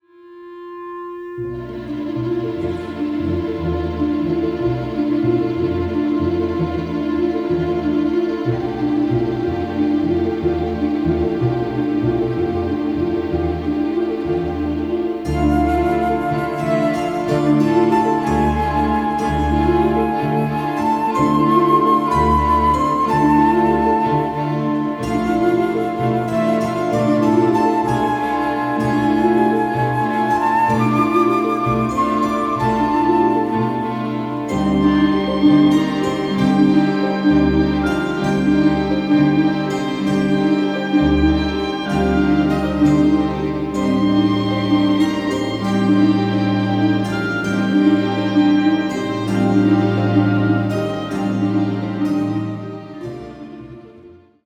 a delicate and poetic score
Remastered from the scoring session tapes